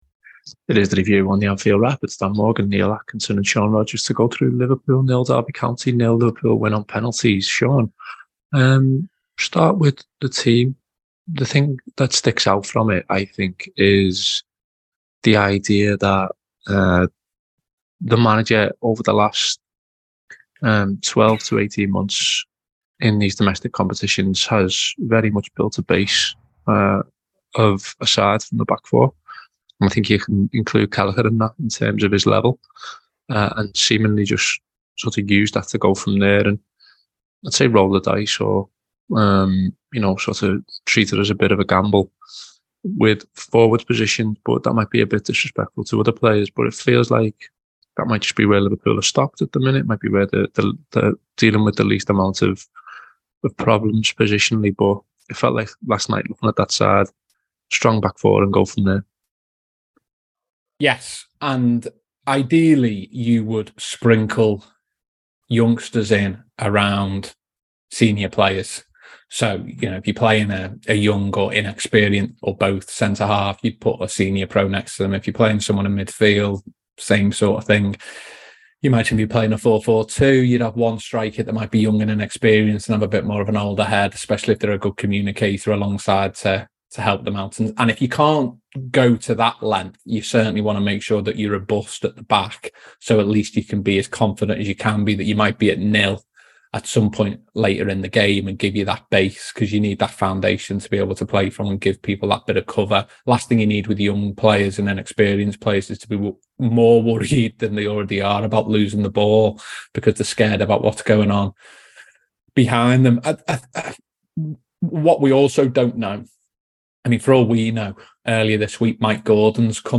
Below is a clip from the show – subscribe for more review chat around Liverpool 0 (3) Derby County 0 (2)…